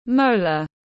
Răng hàm tiếng anh gọi là molar, phiên âm tiếng anh đọc là /ˈməʊ.lər/.